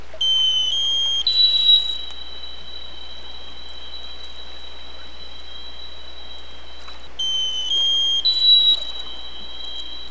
US Navy Sonar.wav